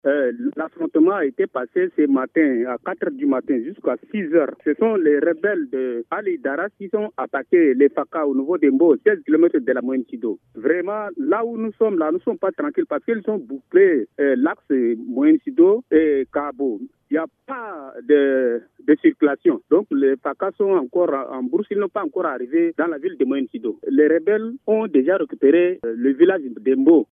Témoignage d’une autorité locale :